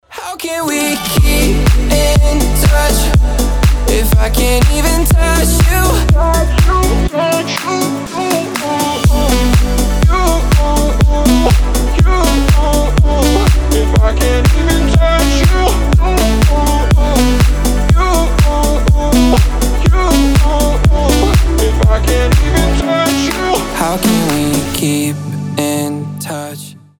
красивый мужской голос
pop house